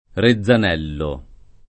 [ re zz an $ llo ]